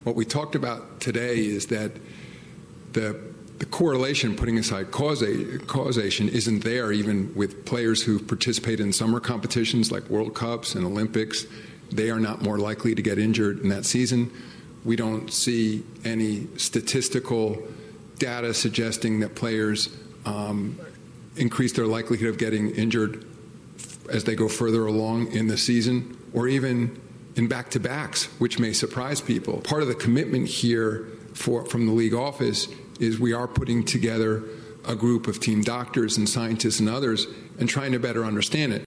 NBA commissioner Adam Silver discussed this policy in a press conference with the media yesterday.